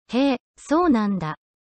こちらは何の調整もしない素のままのテキスト読み上げ、
へー、そうなんだ_チューニング前.mp3